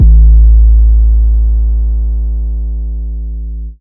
808 Kick 31_DN.wav